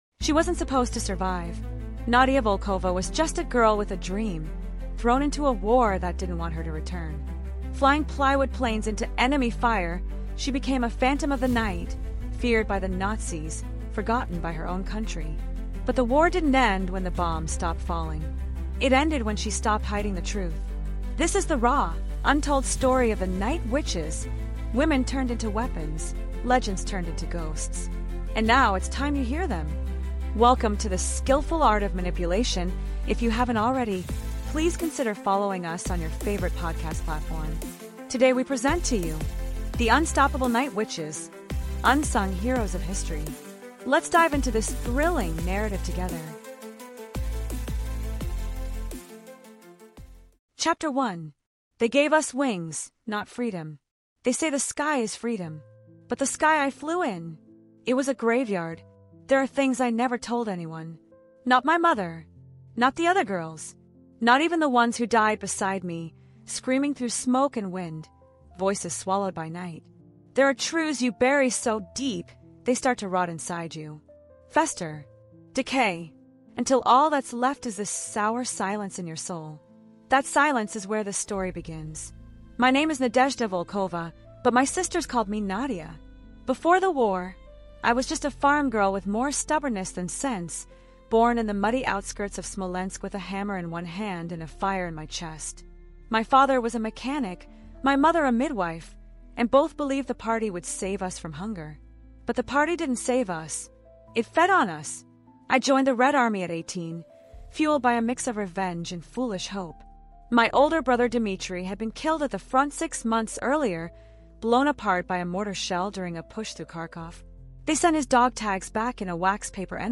The Unstoppable Night Witches: Unsung Heroes of History is a searing, emotionally immersive 7-chapter audiobook told in the first-person voice of Nadia Volkova—a forgotten Soviet pilot who helped terrorize Nazi forces in WWII as part of the infamous “Night Witches.” Armed with outdated biplanes, no parachutes, and a war machine that saw them as disposable, these women flew endless night raids, striking fear into enemies from above. But behind the medals and myths lies a deeper story—one of betrayal, manipulation, and a sisterhood bound by grief and fire.